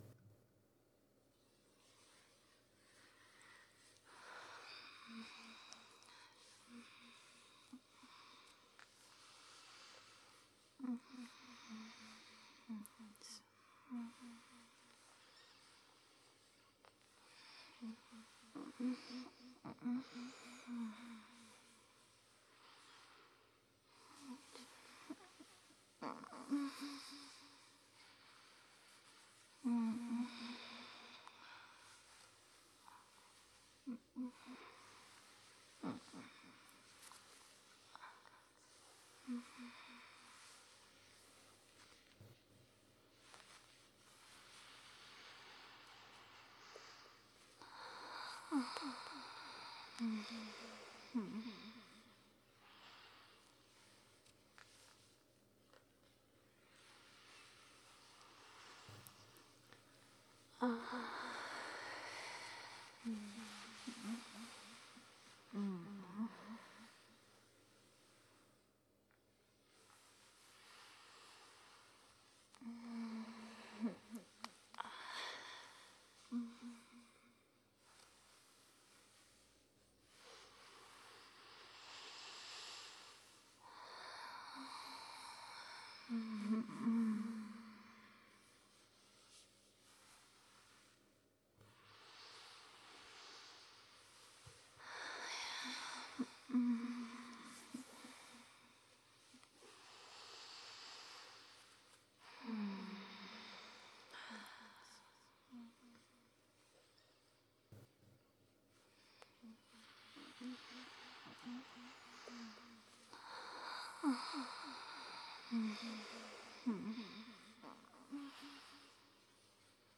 Soft Spoken
This audio includes minimal talking, instead focusing on looping softly murmured happy sighs and sleepy moans.
Layers, Echoes
A short, loopable background that captures the sweet ASMR of crumpling sheets, sleepy, happy sounds, and a comfy breathing with a soft touch of echo.